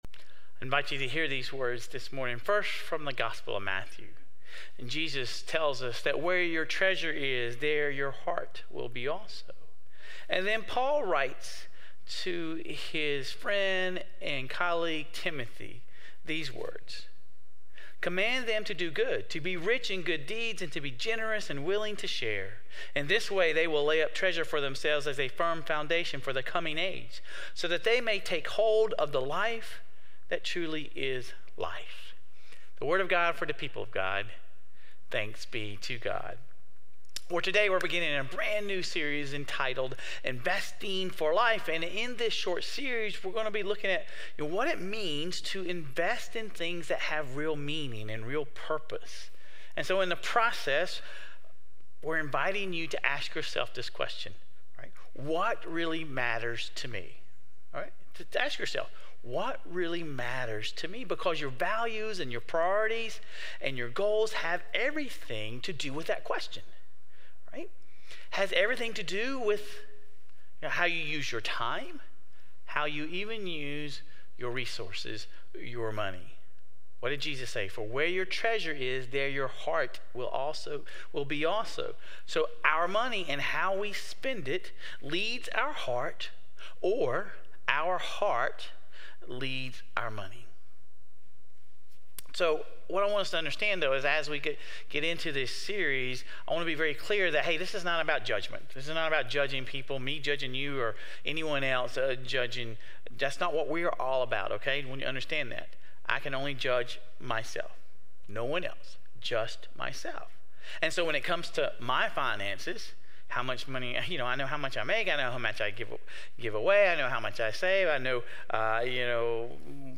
Sermon Reflections: Reflect on your current spending habits.